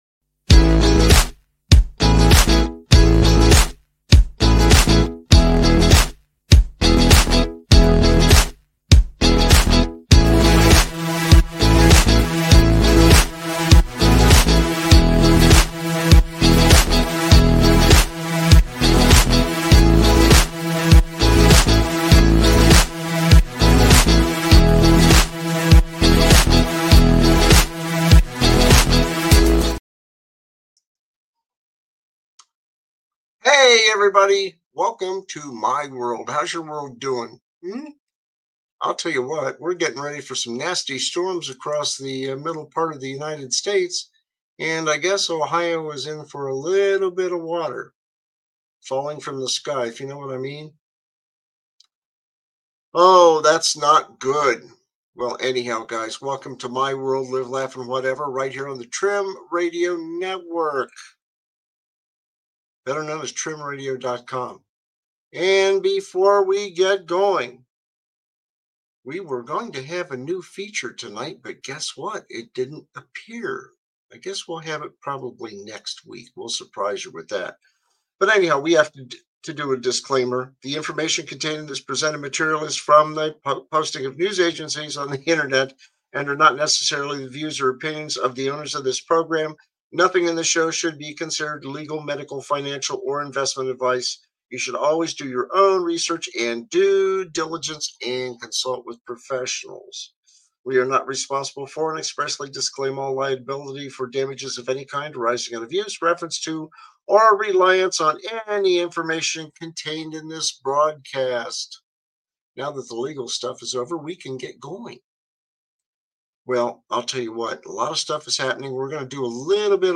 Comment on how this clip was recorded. Expect plenty of laughter, thought-provoking discussions, and honest conversations.